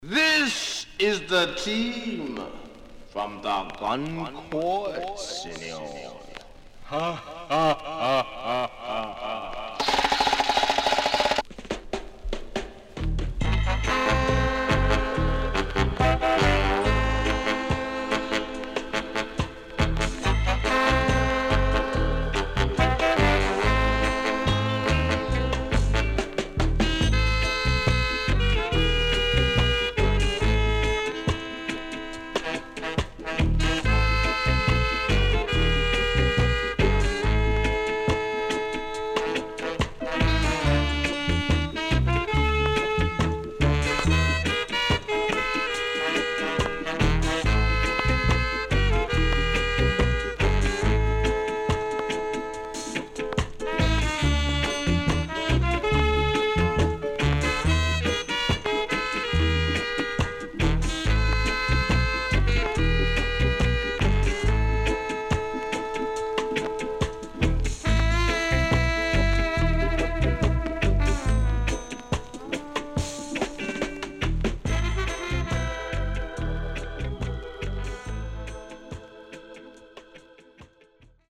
CONDITION SIDE A:VG(OK)
Horn Inst & Dubwise
SIDE A:所々チリノイズがあり、少しプチノイズ入ります。